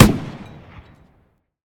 tank-mg-shot-3.ogg